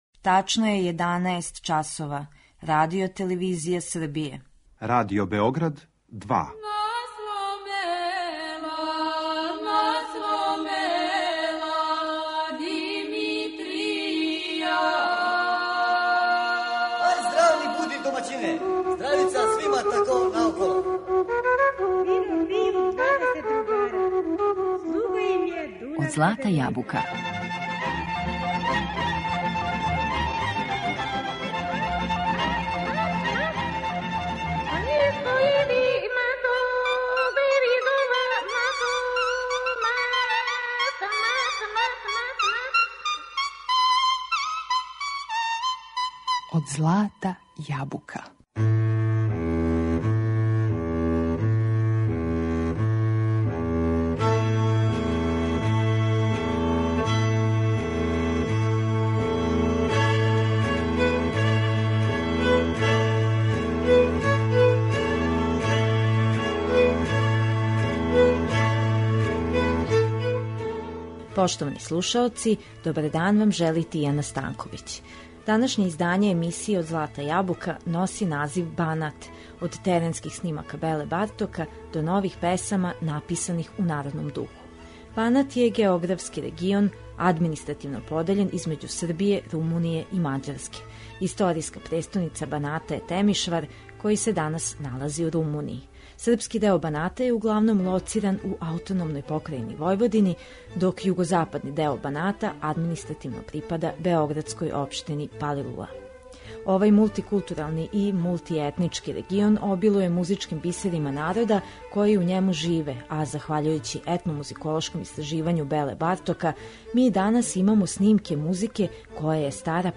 Данашње издање емисије Од злата јабука носи назив Банат - од теренских снимака Беле Бартока до нових песама написаних у народном духу.
Овај мултикултурални регион подељен између три земље - Србије, Румуније и Мађарске, обилује музичким бисерима из традиције сва три народа, а захваљујући истраживању Беле Бартока, звучни снимци народне музике датирају од пре више од сто година. Емисија је посвећена вокалним и инструменталним примерима из Баната који се преносе са колена на колено, као и компонованим песмама које су, захваљујући својој лепоти, постале део традиционалног музичког репертоара.